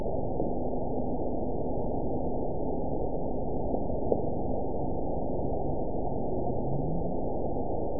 event 911429 date 02/26/22 time 23:41:54 GMT (3 years, 2 months ago) score 9.39 location TSS-AB01 detected by nrw target species NRW annotations +NRW Spectrogram: Frequency (kHz) vs. Time (s) audio not available .wav